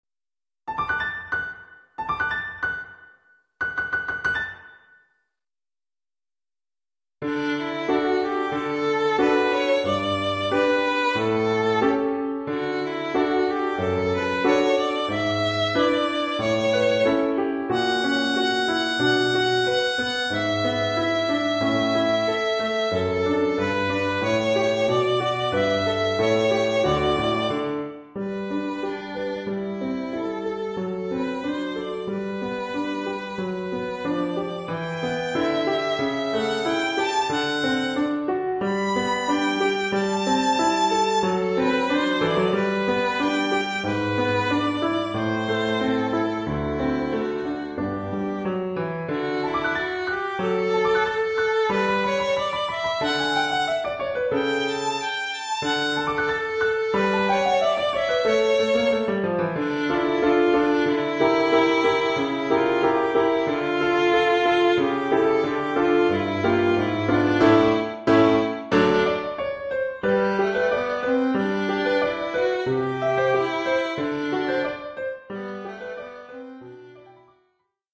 Formule instrumentale : Violon et piano
Oeuvre pour violon avec
accompagnement de piano.